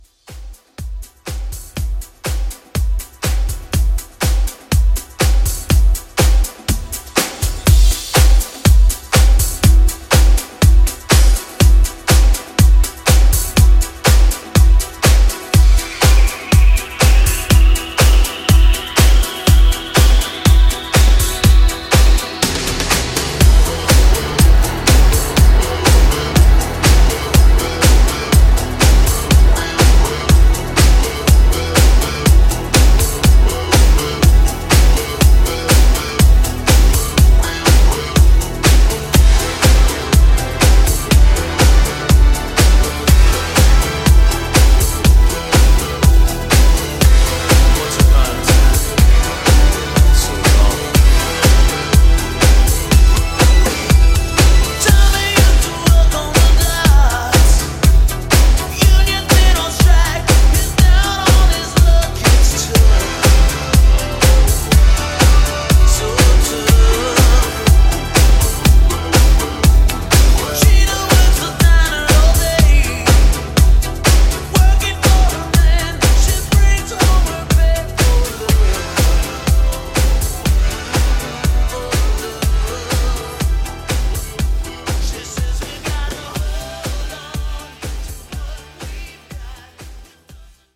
Genres: MOOMBAHTON , RE-DRUM , TOP40
Clean BPM: 108 Time